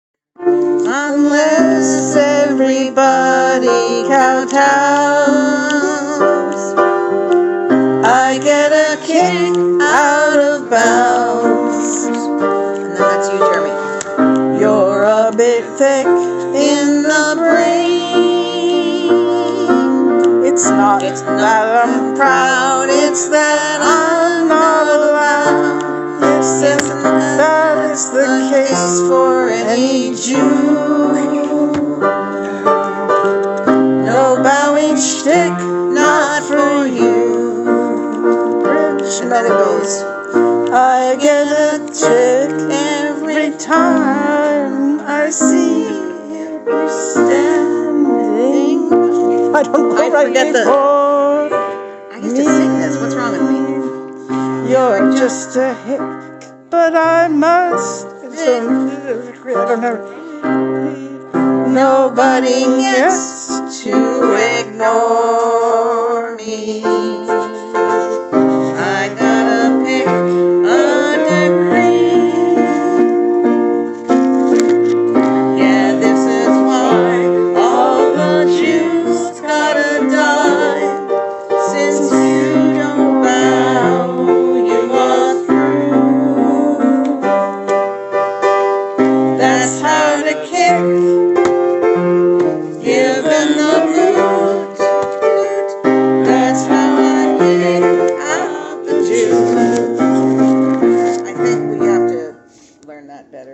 Rough recordings.
Tempos on some things could get faster when we are more familiar.